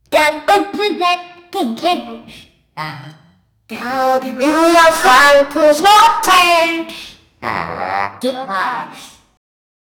panty diarrhea
panty-diarrhea--f3tp6rvi.wav